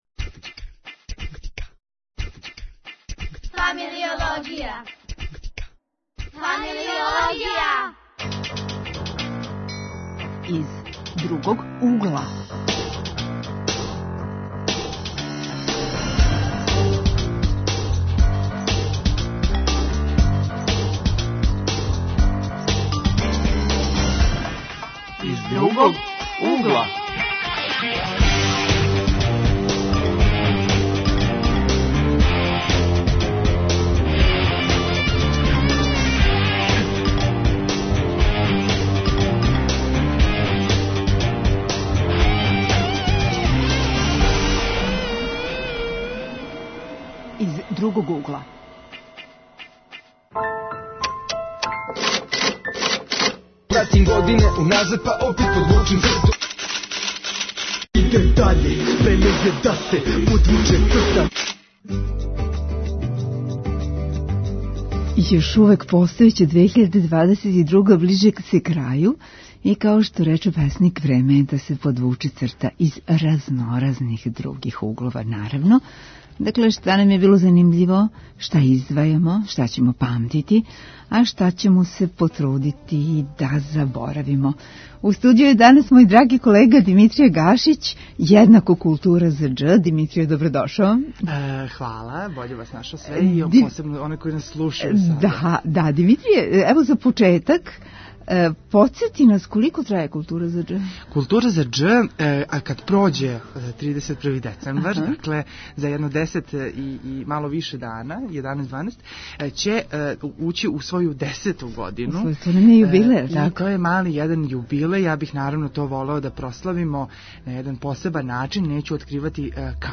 Подвлачимо црту под 2022. Или, 2022. из разноразних других углова: из културолошког, уметничког, енциклопедијског, социјалног...Гости у студију су студенти.